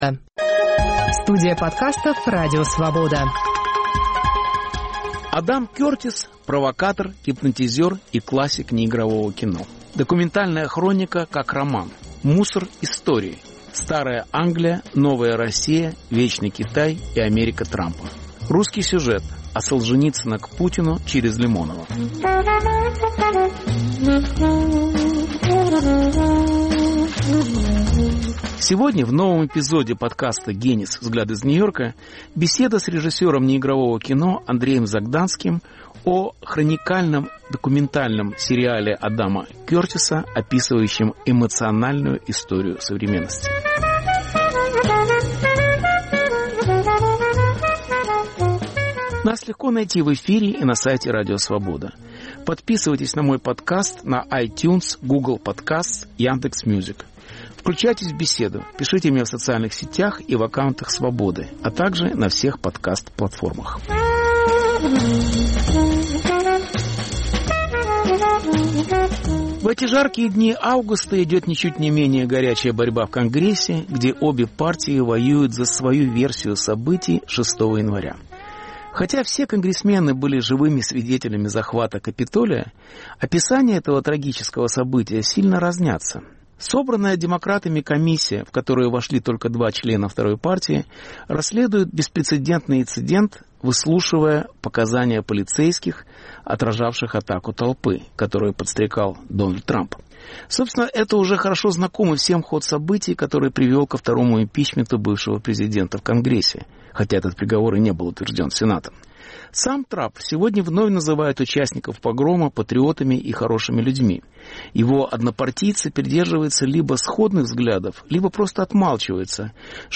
Документальный сериал Адама Кёртиса: диагноз кризиса. Беседа